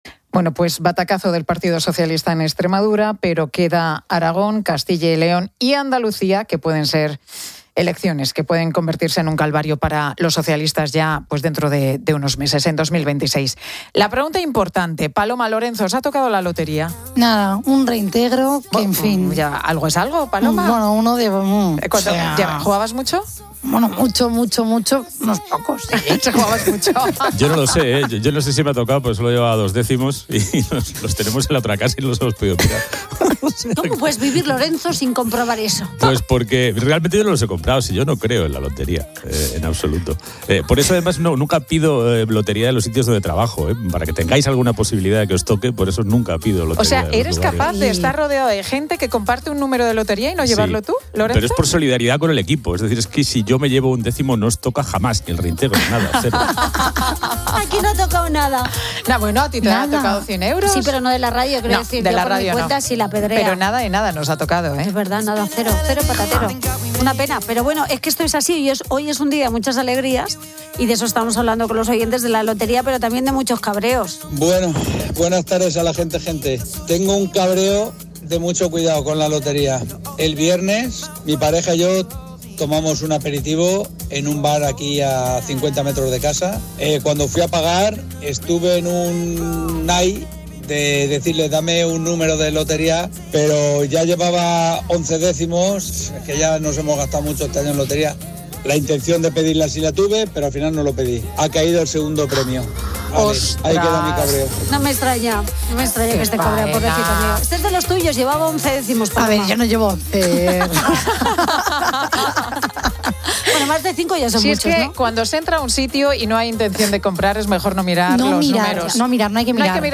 Oyente de La Tarde
Este no ha sido el único caso de suerte esquiva compartido en antena.